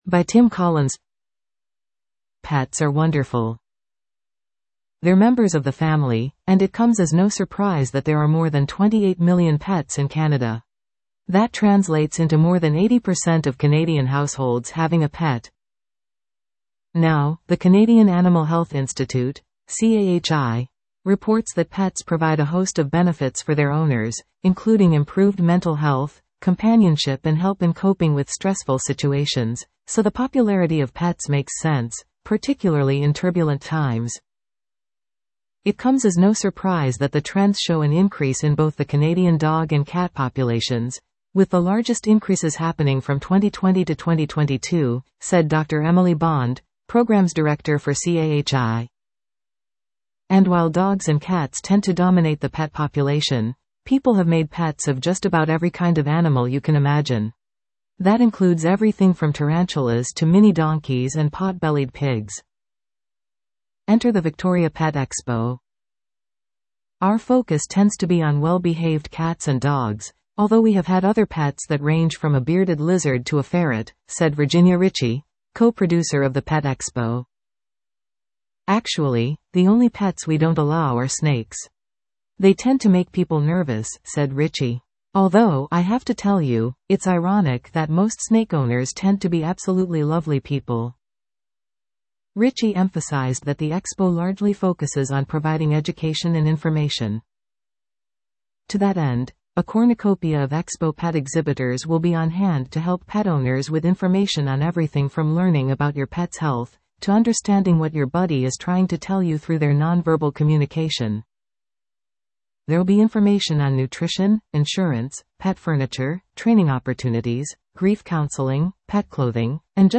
(Contributed) Listen to this article 00:03:19 By Tim Collins Pets are wonderful.